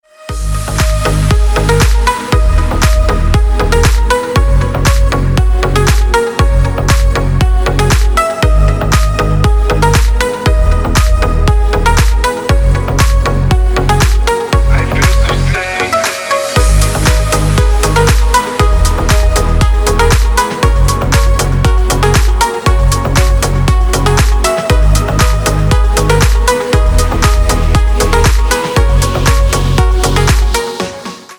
• Качество: 320, Stereo
мужской голос
ритмичные
Electronic
EDM
спокойные
качающие
Стиль: deep house